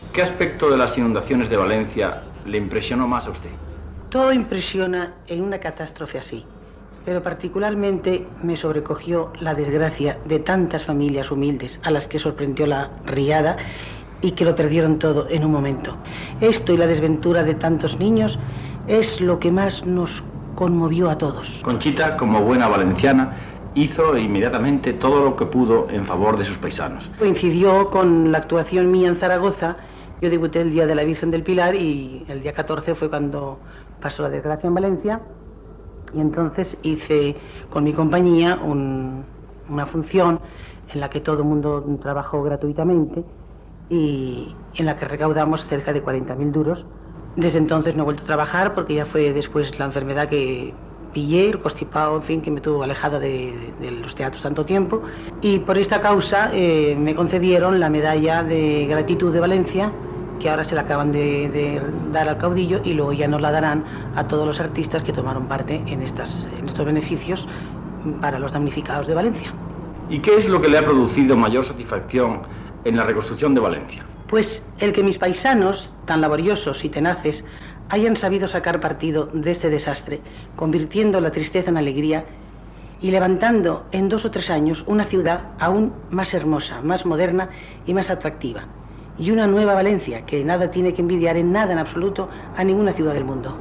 La cantant Concha Piquer parla de la riuada de València, ocorruguda el mes d'octubre de l'any 1957
Entreteniment